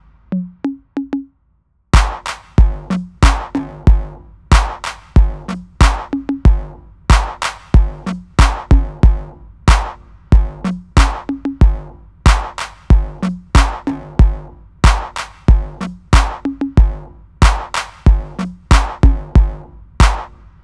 08 drums B.wav